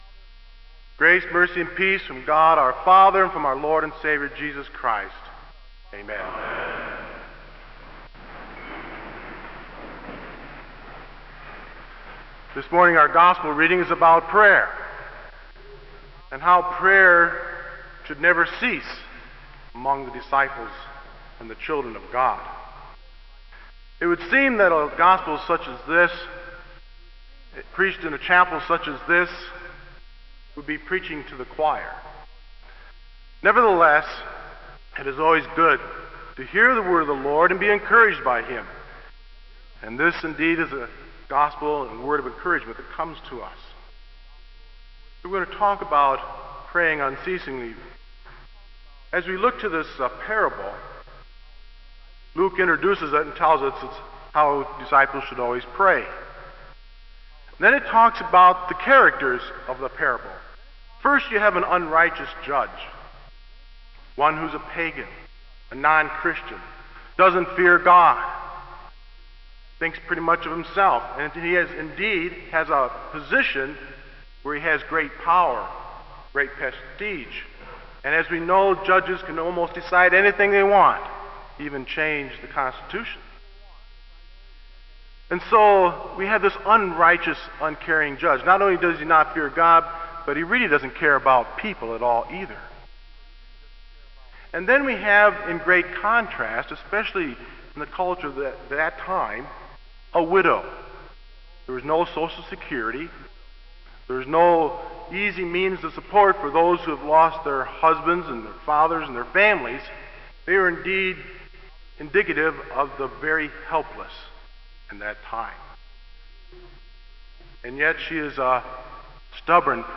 Kramer Chapel Sermon - October 24, 2001